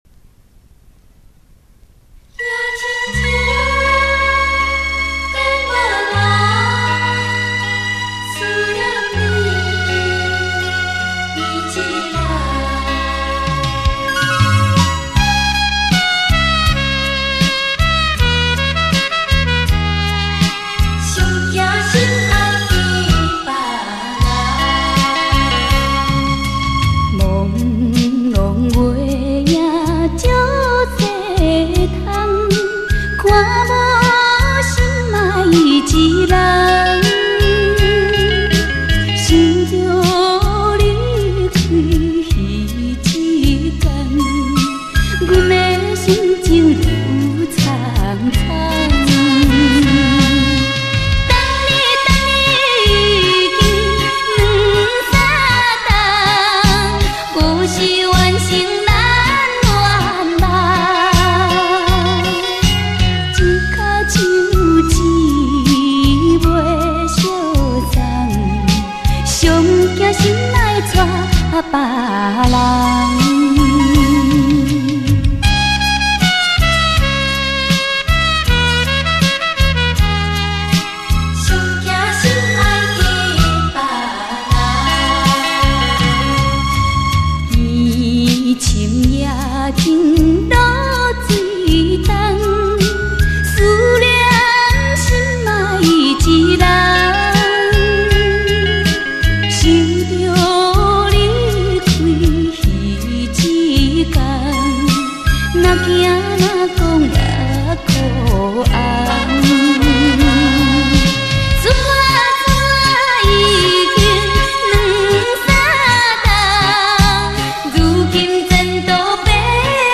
福建流行歌曲